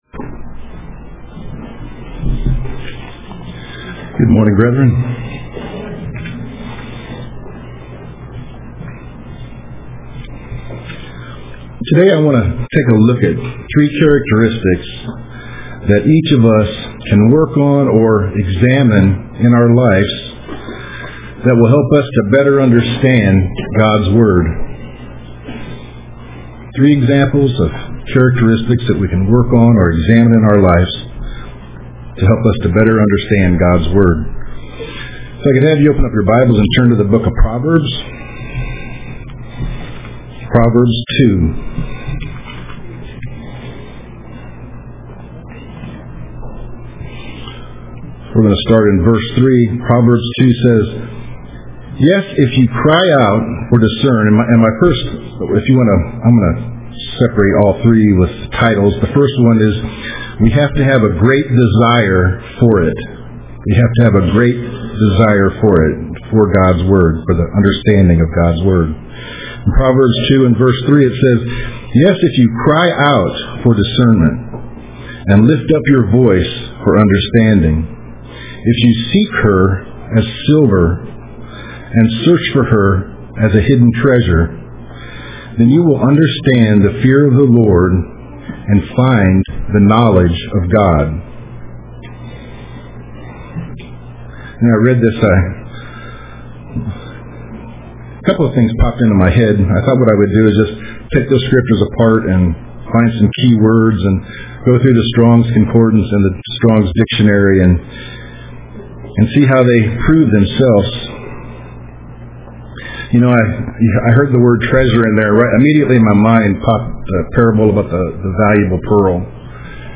Print X-Marks the Spot UCG Sermon